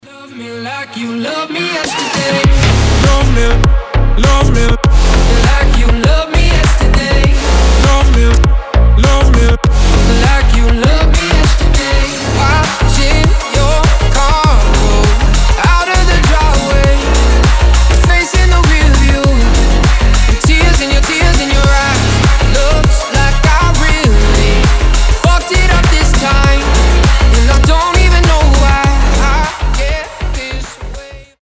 мужской голос
громкие
EDM
future house
басы